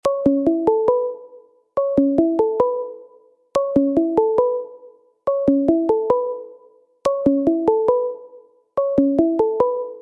اهنگ زنگ ساده ملایم